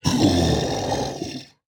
evil-rabbit-v1.ogg